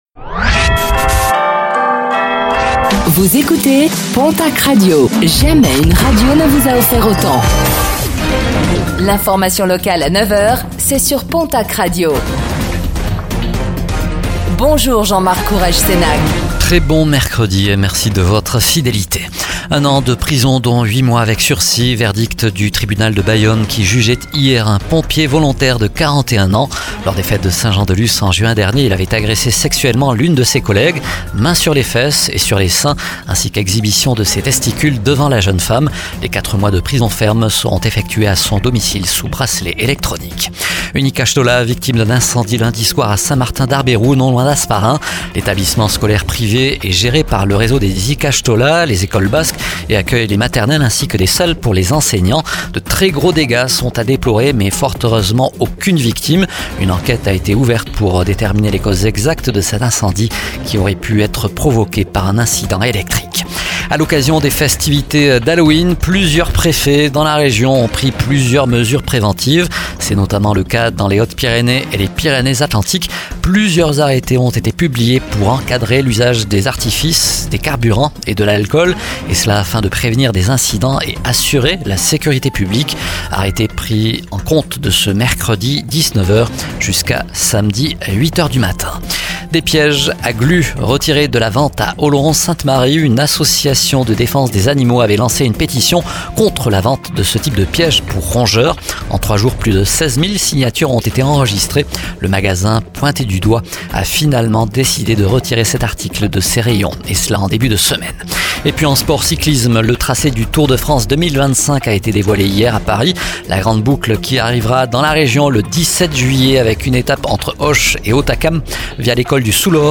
Réécoutez le flash d'information locale de ce mercredi 30 octobre 2024